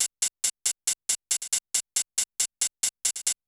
8TH HH    -R.wav